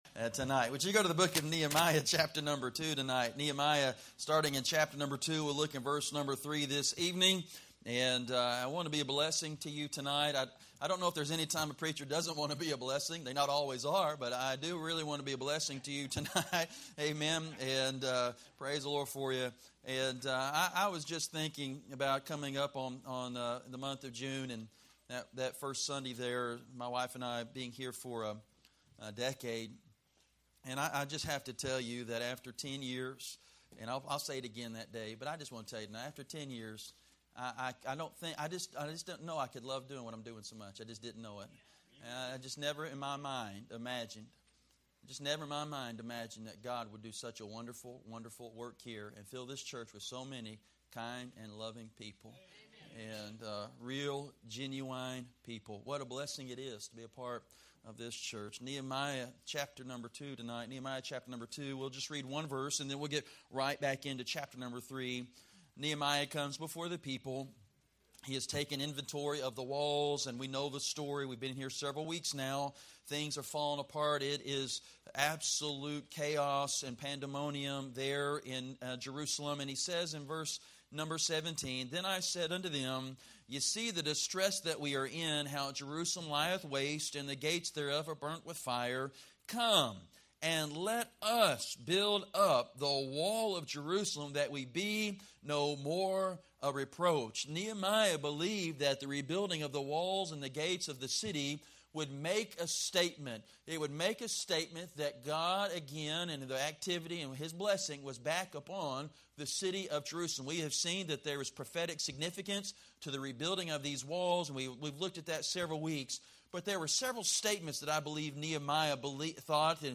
Sermons – Pioneer Baptist Church of Citrus Heights, CA